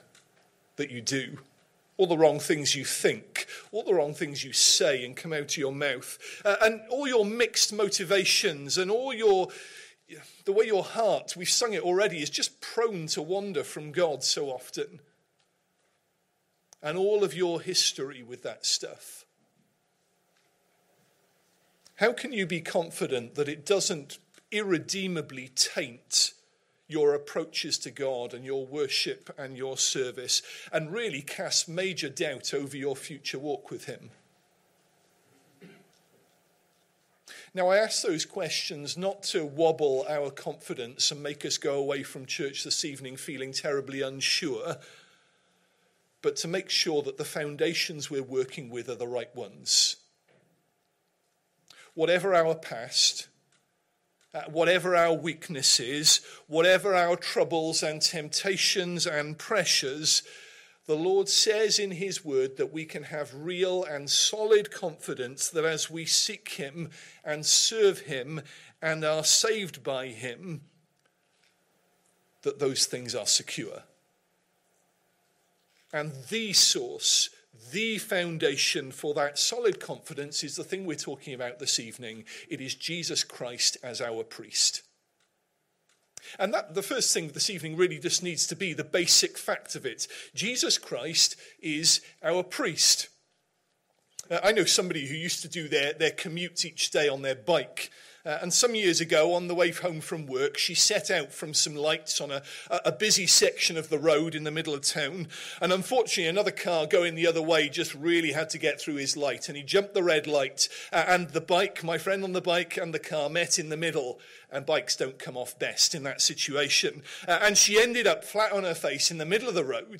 Sunday PM Service